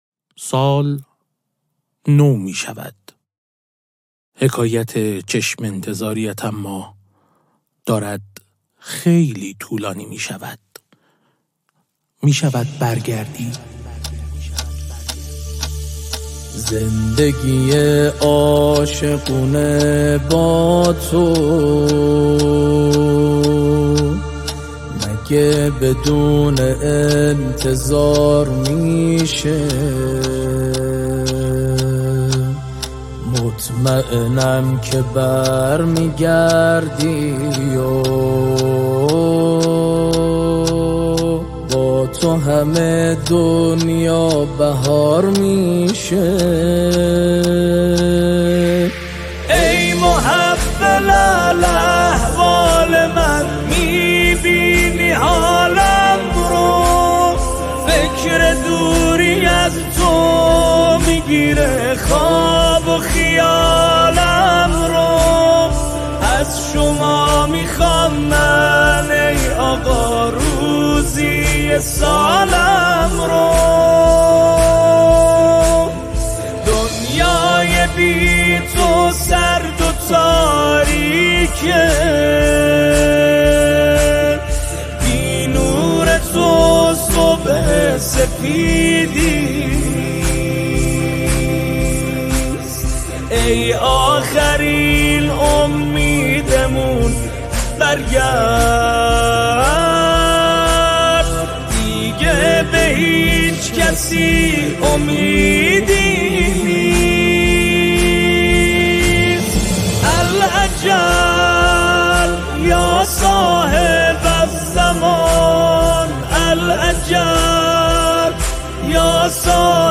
ویژه عید نوروز و لحظه تحویل سال